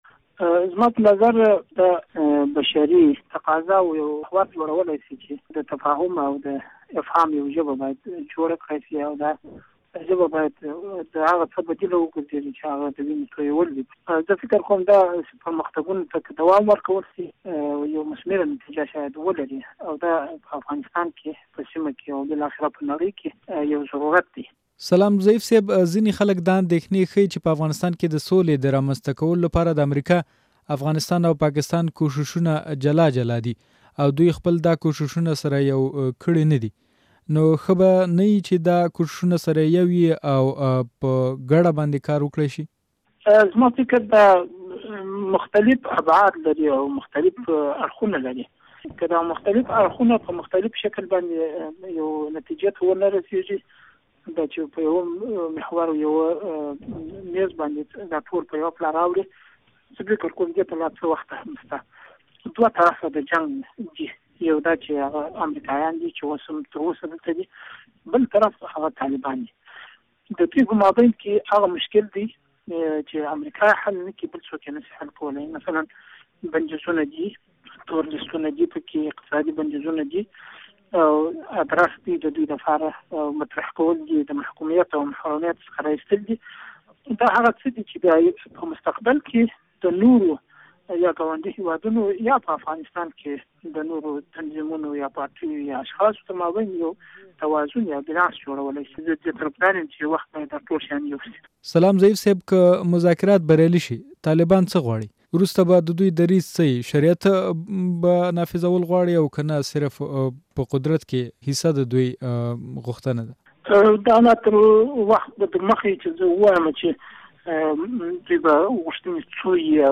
له ملا عبدالسلام ضعيف سره مرکه